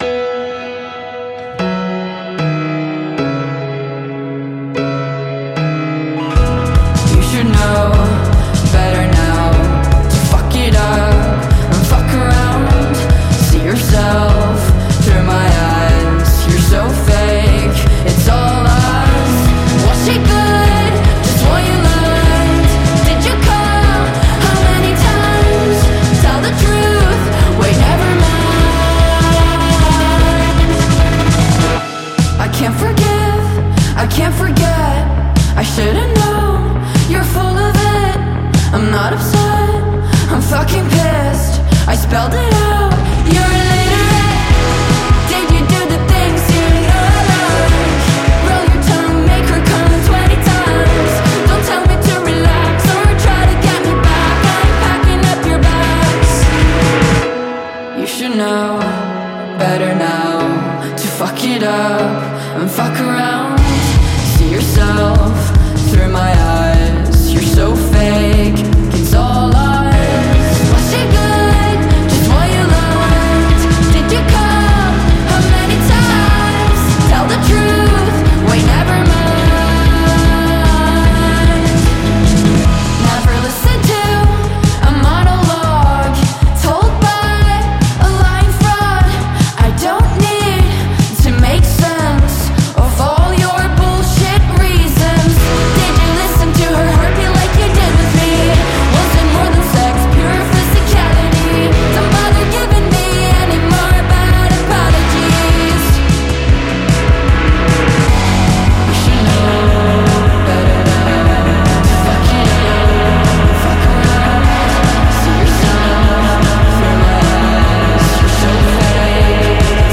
Dream Pop